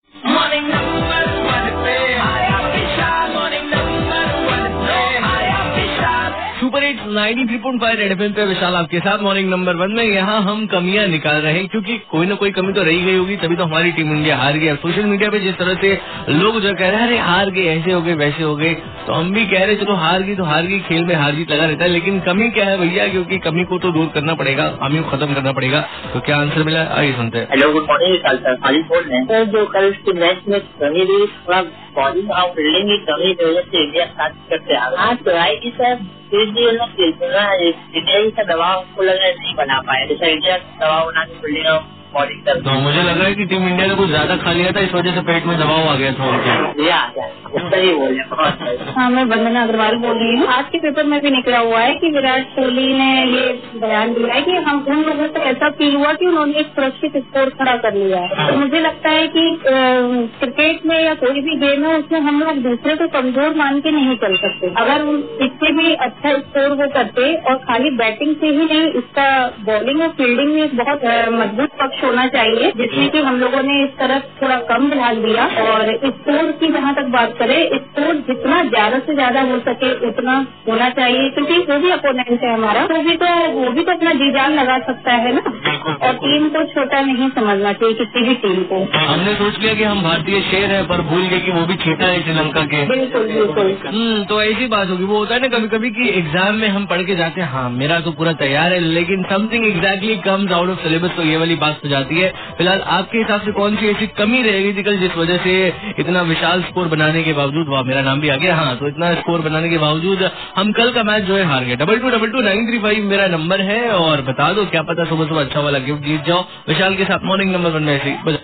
CALLER'S BYTE